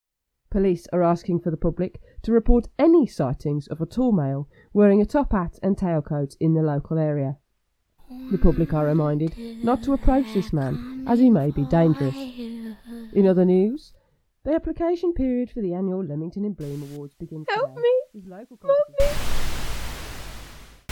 Sound Effects for a live Halloween Scare Theatre event